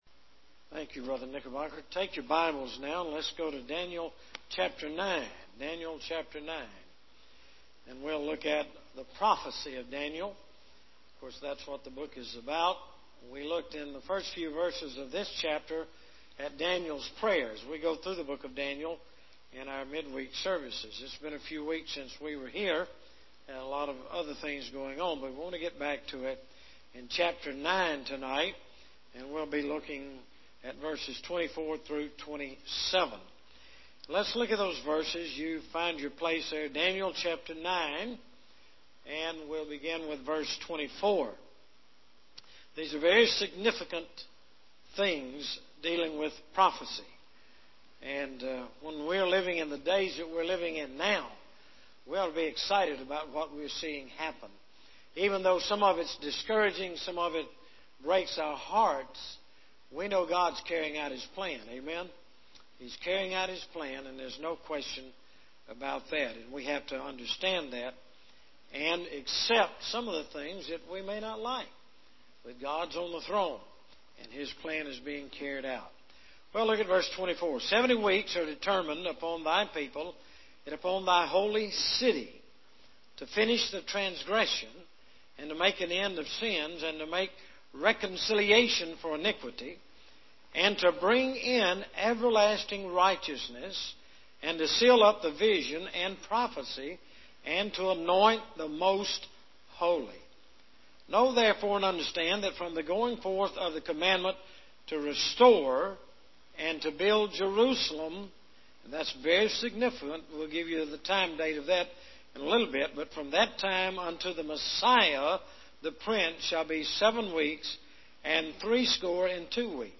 Book Study of Daniel - The Prophecy of Daniel